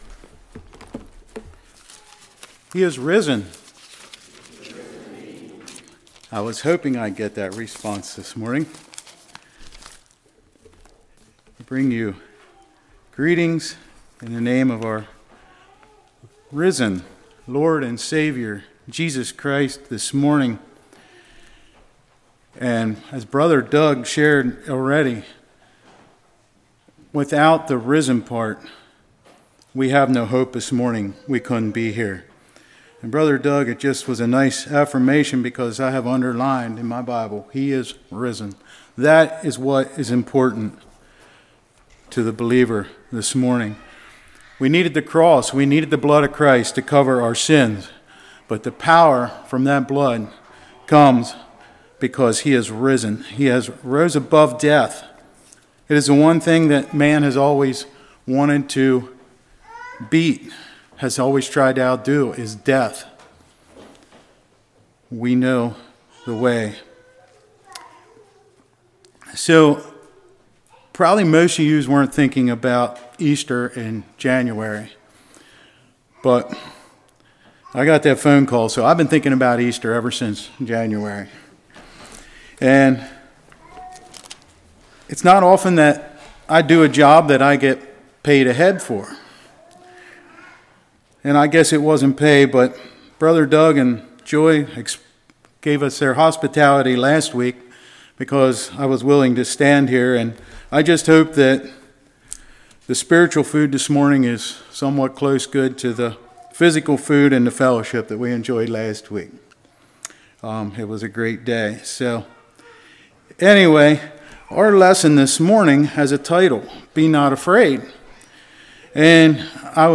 Passage: Mark 16:1-8 Service Type: Sunday School